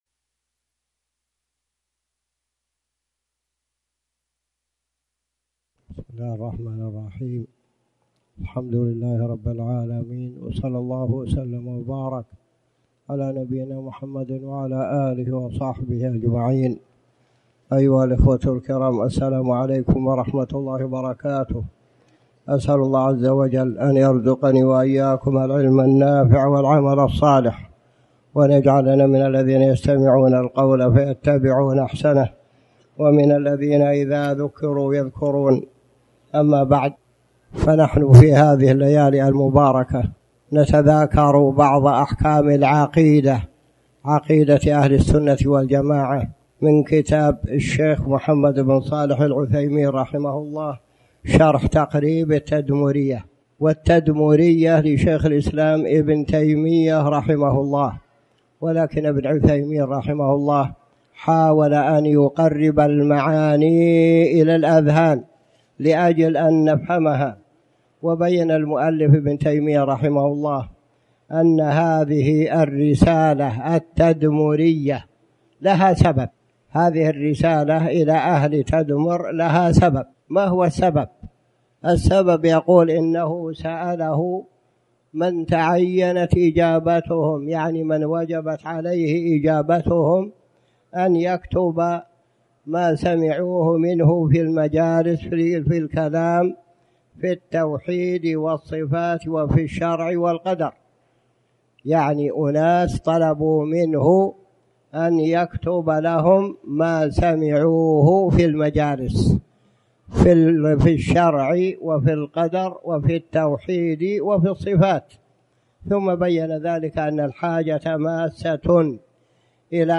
تاريخ النشر ١ ذو القعدة ١٤٣٩ هـ المكان: المسجد الحرام الشيخ